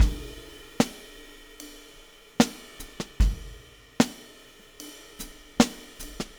Weathered Beat Crash 01.wav